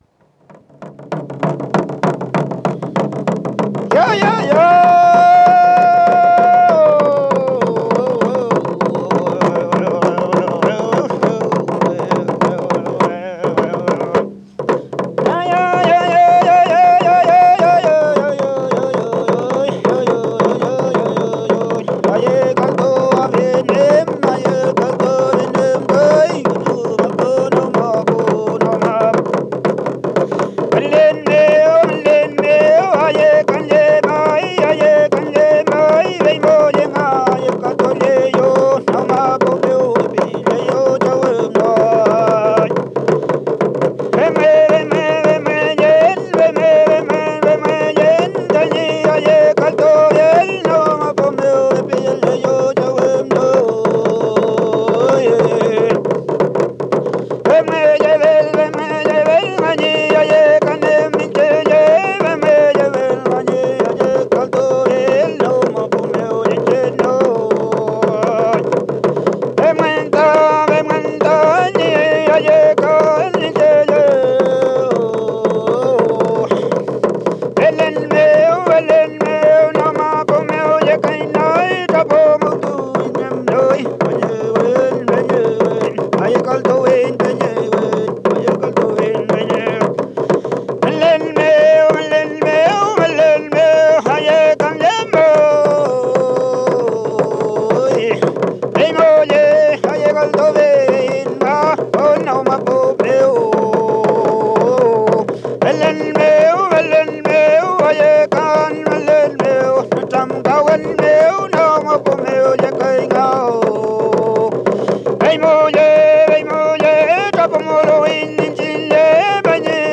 Música mapuche (Comunidad Laguna, Lumaco)
Música vocal
Música tradicional